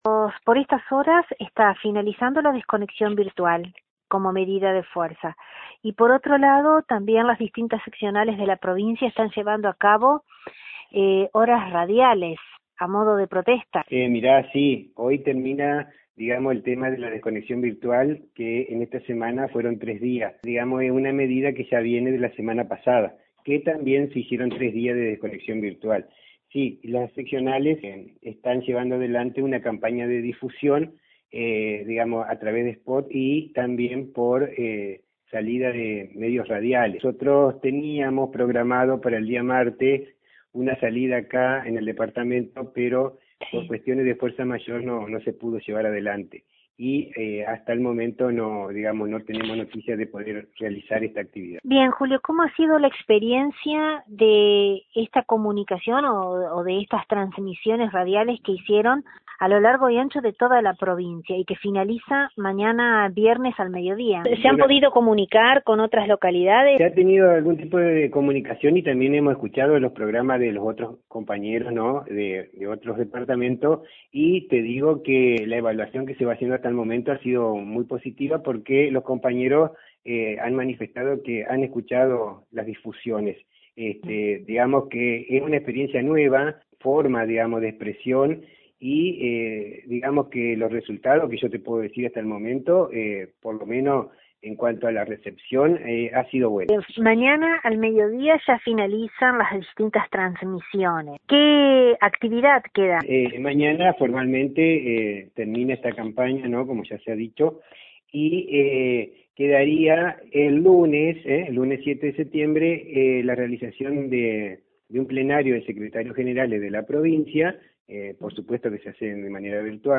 En franco diálogo con nuestro medio